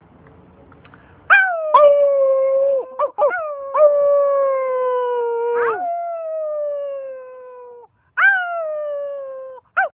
Coyote_2000.amr